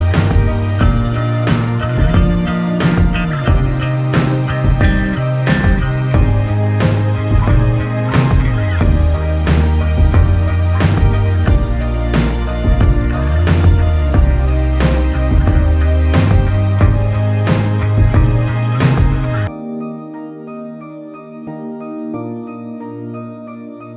Minus All Guitars Pop (2010s) 2:56 Buy £1.50